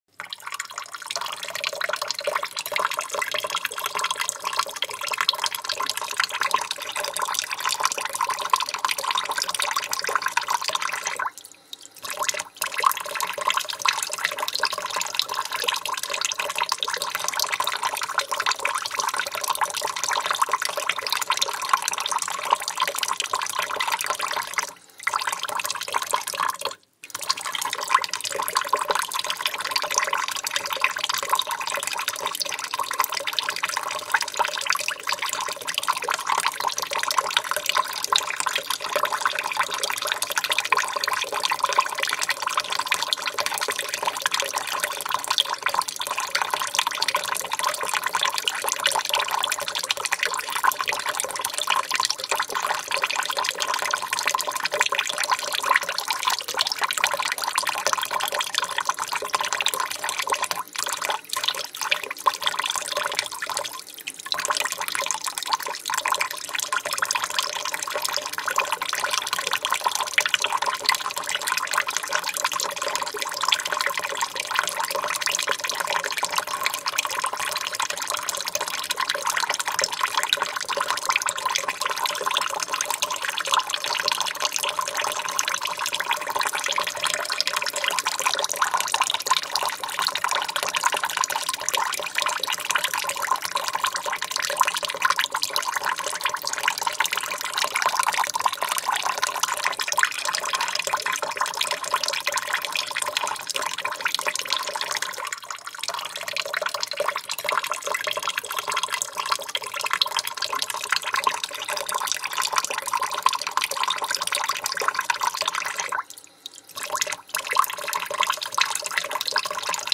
Zvuk-dlya-rebenka-chtob-popisal 38559
• Kategoria: Dźwięki wody do sikania 1195